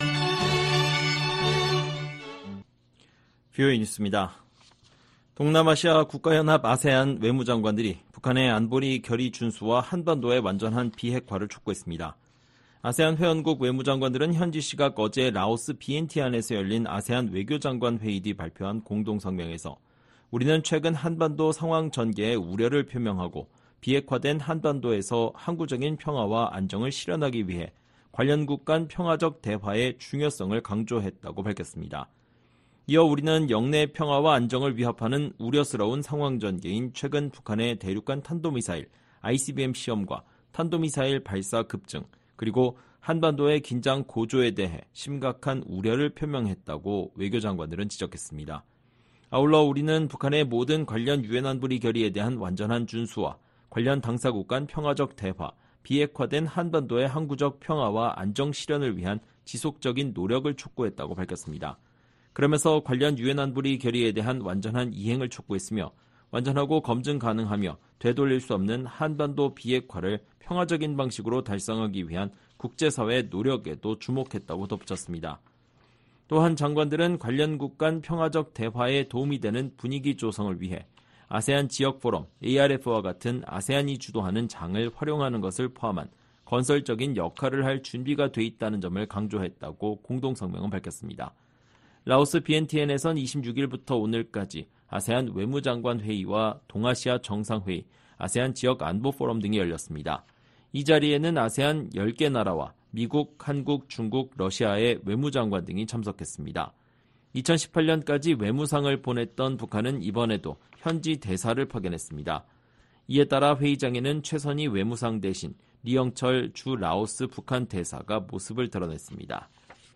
VOA 한국어 방송의 일요일 오후 프로그램 2부입니다. 한반도 시간 오후 9:00 부터 10:00 까지 방송됩니다.